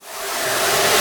VEC3 Reverse FX
VEC3 FX Reverse 50.wav